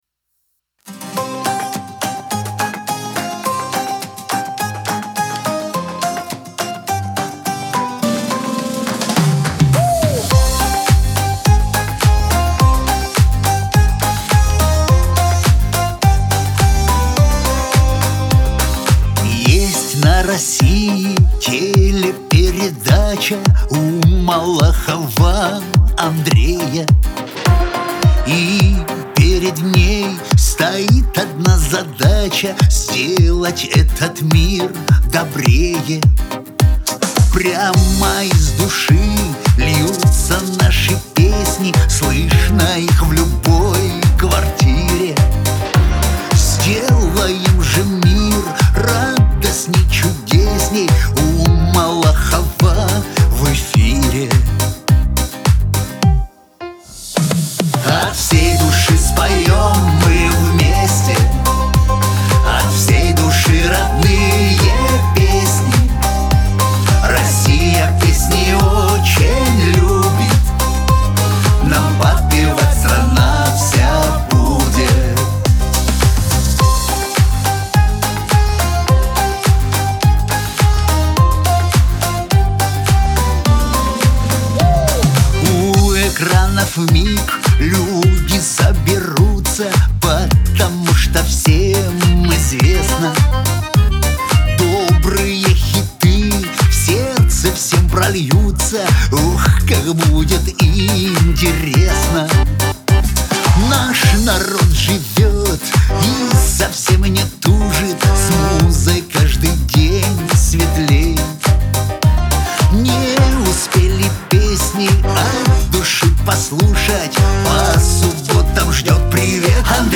pop
Веселая музыка , эстрада